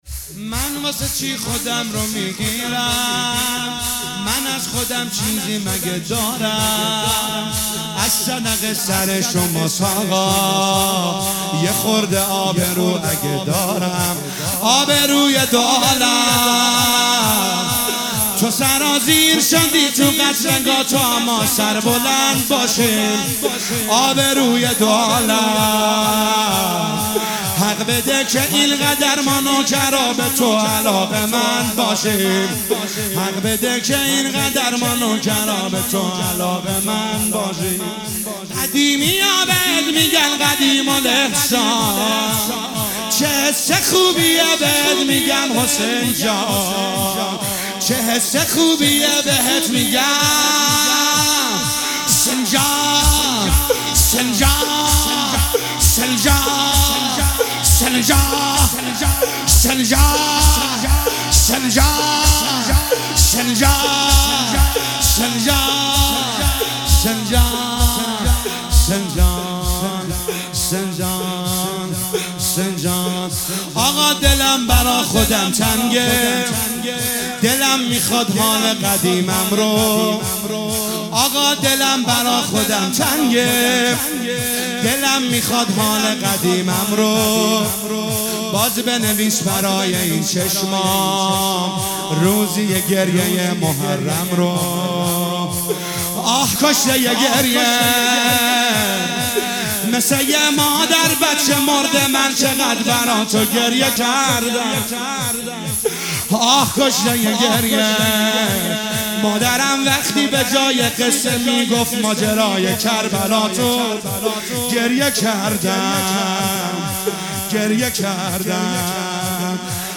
دانلود مداحی شب سوم محرم 1401
شور